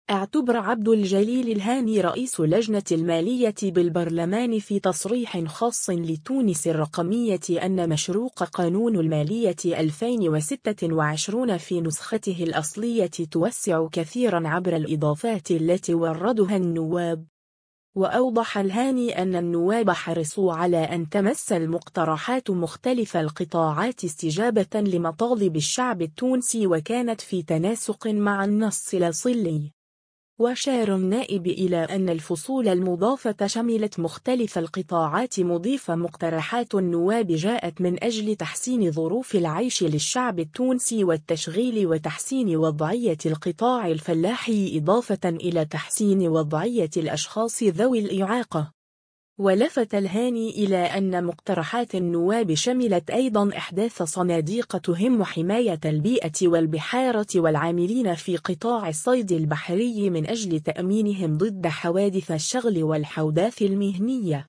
إعتبر عبد الجليل الهاني رئيس لجنة المالية بالبرلمان في تصريح خاص لـ”تونس الرقمية” أن مشروق قانون المالية 2026 في نسخته الأصلية توسع كثيرا عبر الاضافات التي اوردها النواب.